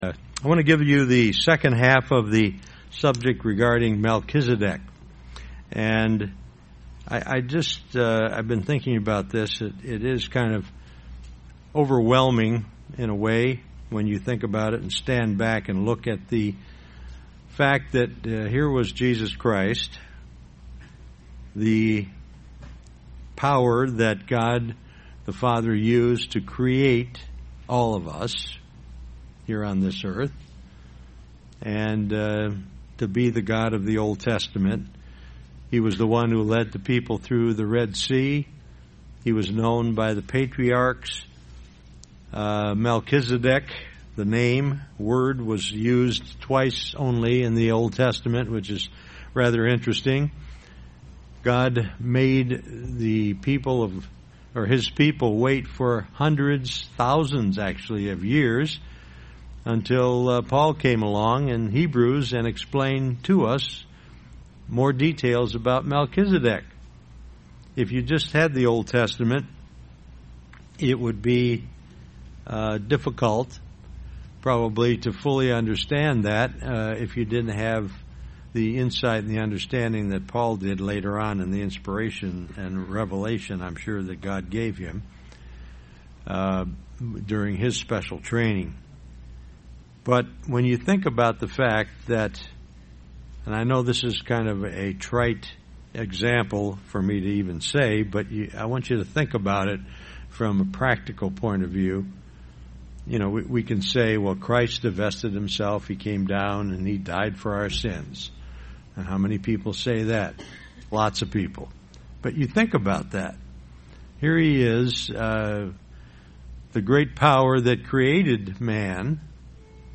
Given in Beloit, WI
UCG Sermon Studying the bible?